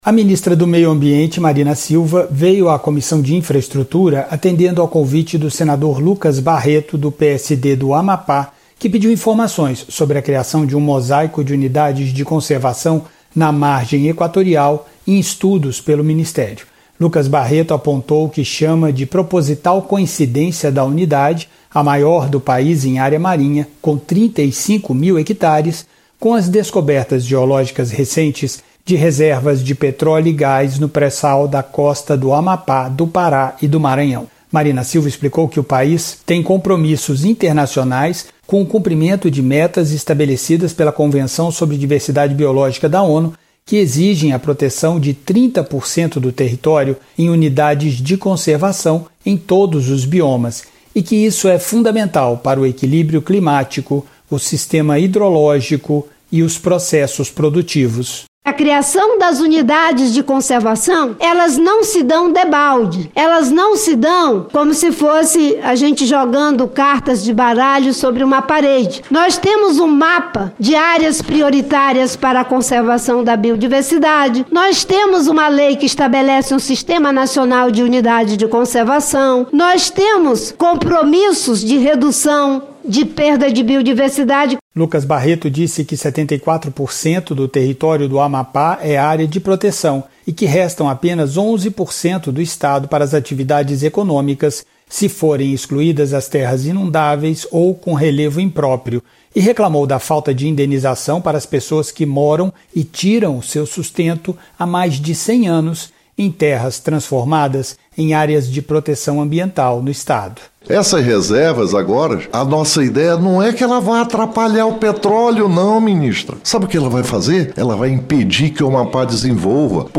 A Comissão de Infraestrutura ouviu a ministra do Meio Ambiente, Marina Silva, sobre a proposta de criação da maior unidade de conservação (UC) marinha do país, com 35 mil hectares, no litoral Norte, desde o Amapá até o Ceará (REQ 99/2024). Marina disse que o Brasil tem o compromisso de preservar 30% do território em UCs.